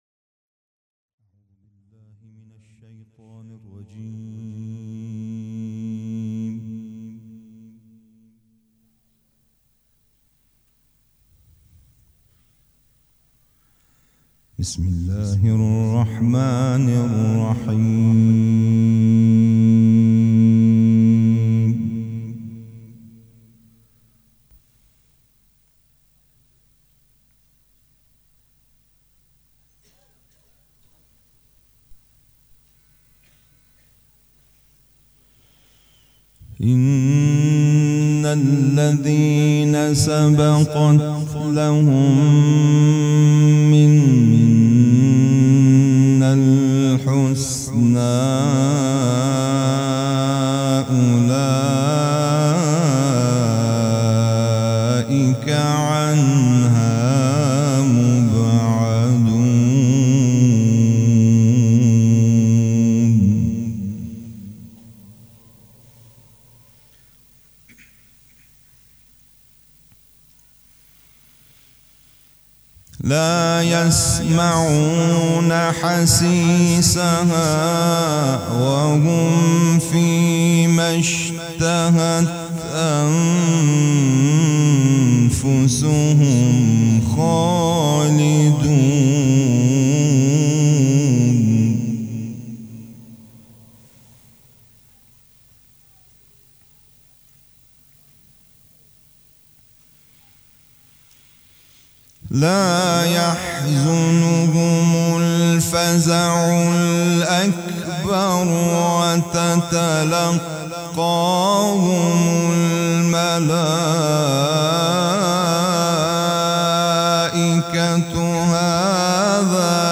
قرائت قرآن
جشن نیمه شعبان